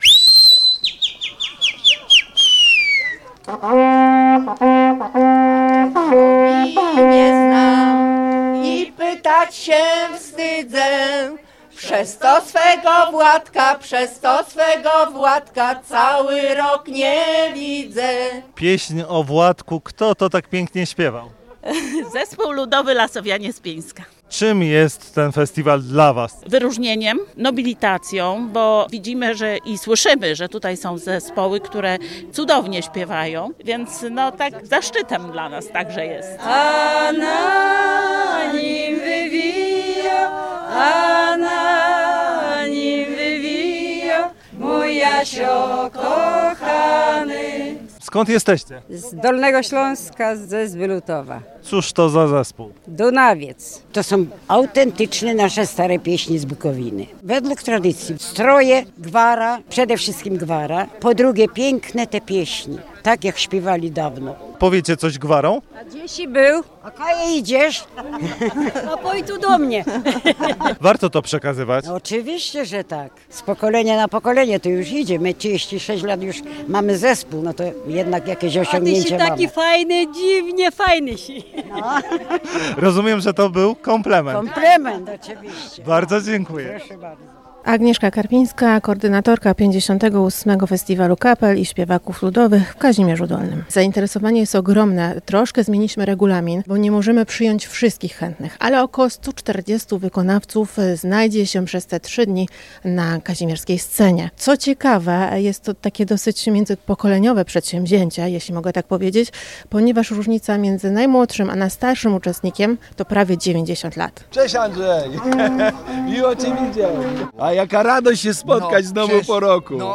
W nadwiślańskim miasteczku rozpoczął się 58. Ogólnopolski Festiwal Kapel i Śpiewaków Ludowych.